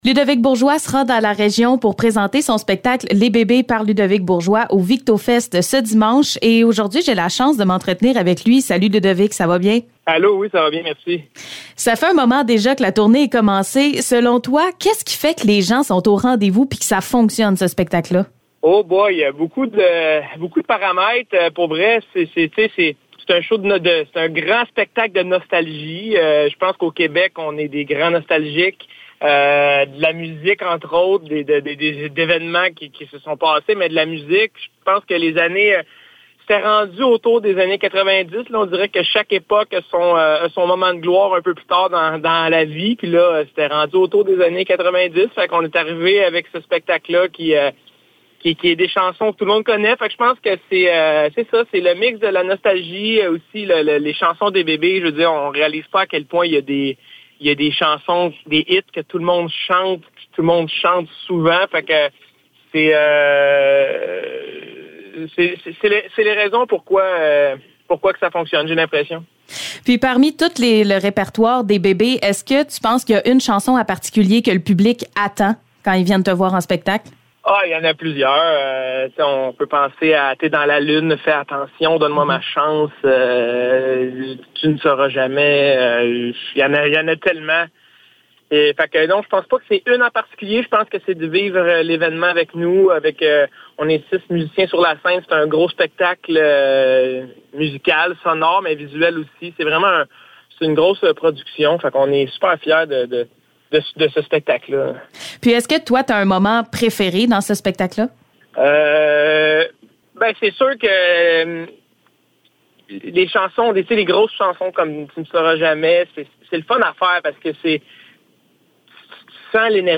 Entrevue avec Ludovick Bourgeois